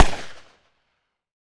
PRC PISTO06L.wav